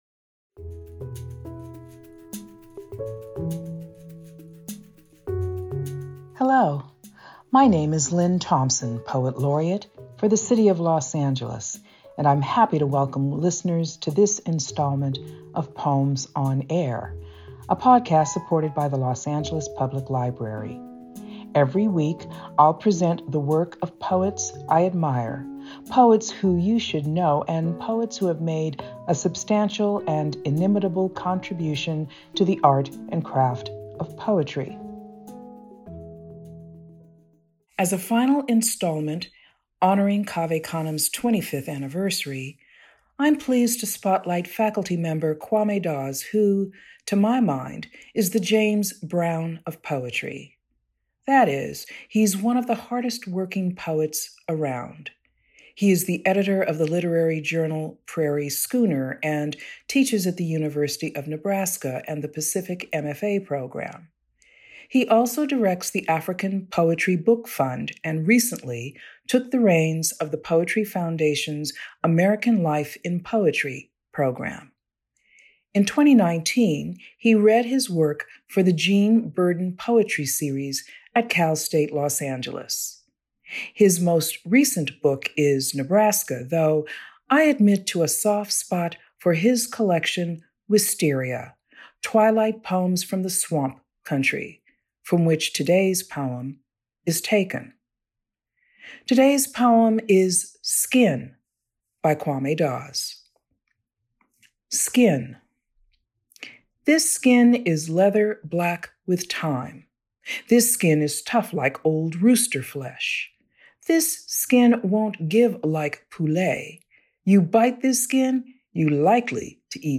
Los Angeles Poet Laureate Lynne Thompson reads Kwame Dawes’s "Skin".